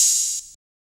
Open Hats
OH - metro.wav